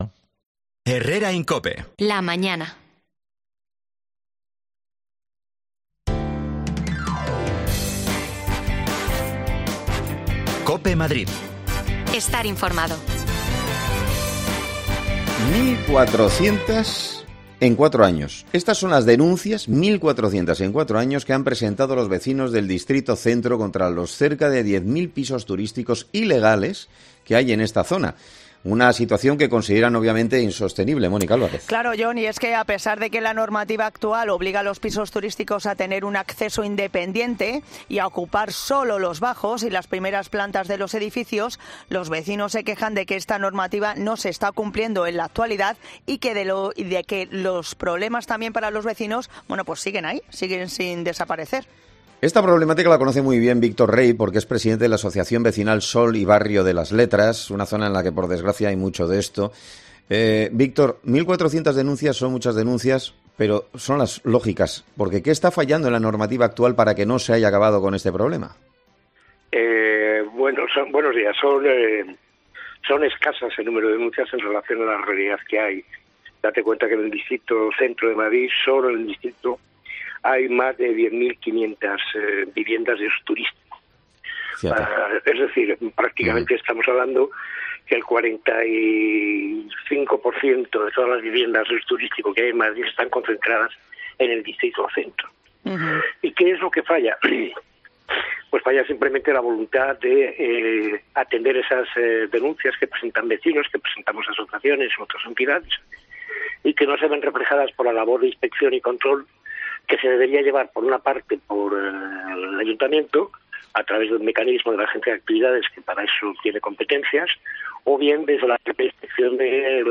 AUDIO: Los pisos turísticos en Madrid siguen acumulando muchas denuncias en nuestra región... Hablamos con vecinos que los sufren en sus barrios
Las desconexiones locales de Madrid son espacios de 10 minutos de duración que se emiten en COPE , de lunes a viernes.